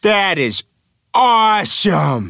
Worms speechbanks
amazing.wav